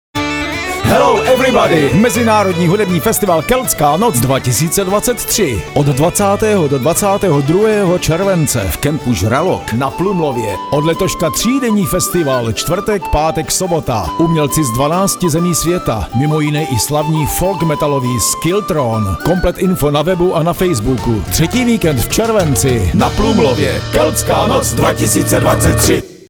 Audiopozvánka KN 2024